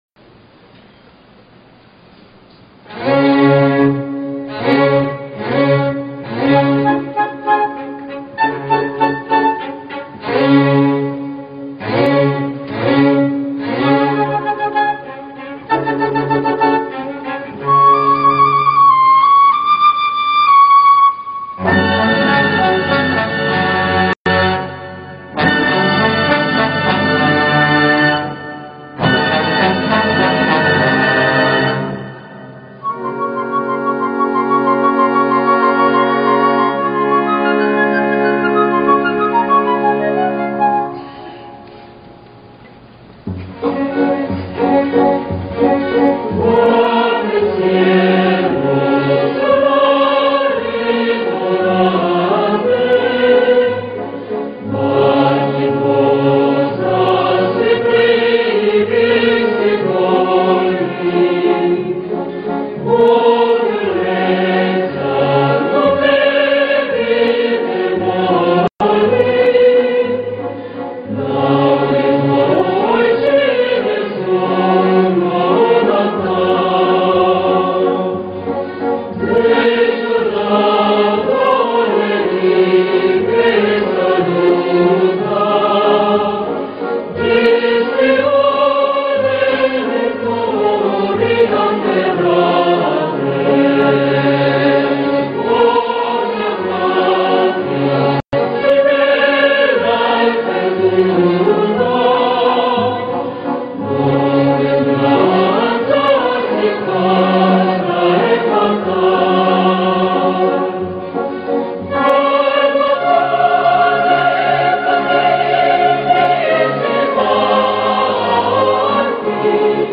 성가공연 히브리 노예들의 합창 좋아요 즐겨찾기 프로그램 소개 프로그램 응원 공유 다운로드 오늘날까지도 많은 그리스도인들에게 사랑을 받으며 불리고 있는 성가들에는 하나님의 영감을 얻어 써내려간 그리스도인들의 간증, 그리고 세상에서 가장 큰 선물인 구원을 주신 하나님에 대한 찬양이 담겨 있다.